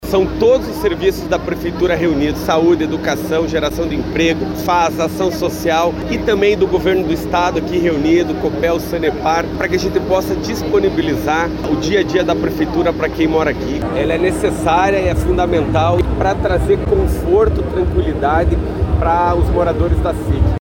Eduardo Pimentel falou sobre os serviços municipais e estaduais oferecidos no local.